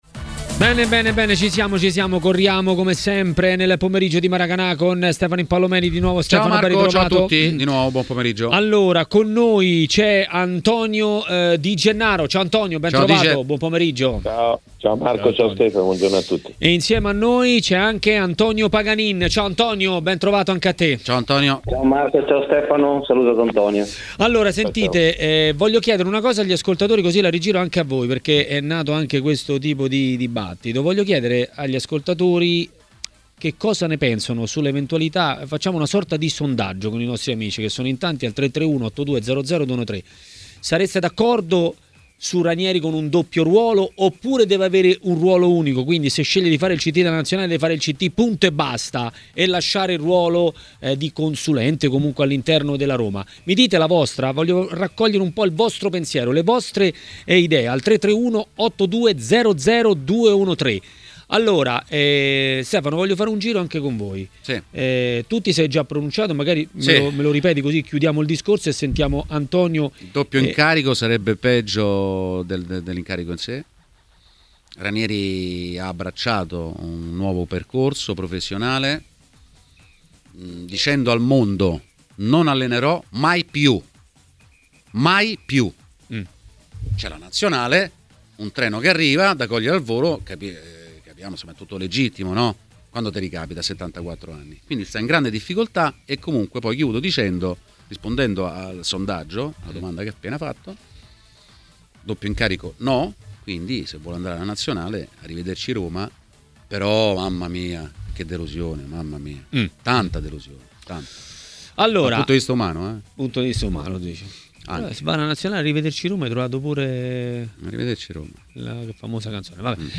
L'ex calciatore e commentatore tv Antonio Di Gennaro è intervenuto a TMW Radio, durante Maracanà.